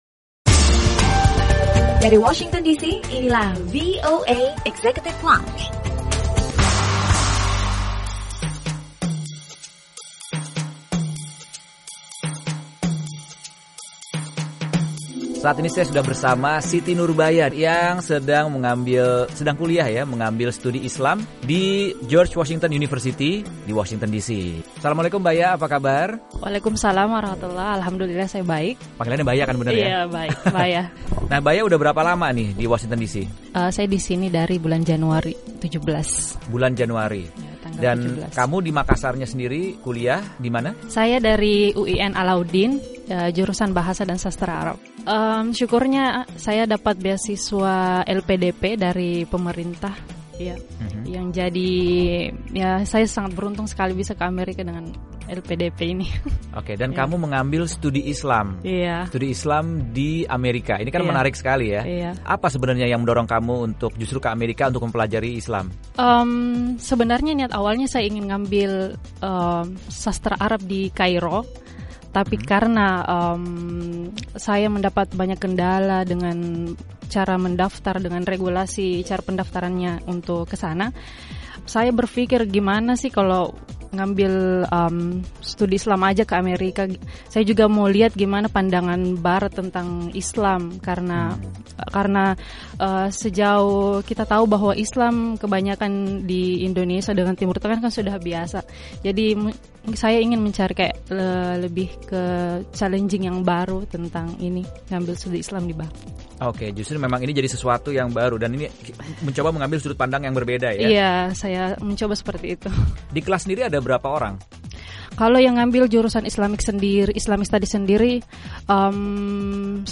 Obrolan bersama seorang mahasiswi Indonesia yang mengambil kuliah S2 jurusan Kajian Islam di George Washington University, Washington DC, seputar latar belakang keinginannya belajar mengenai Islam di Amerika serta berbagai pengalamannya.